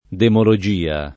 [ demolo J& a ]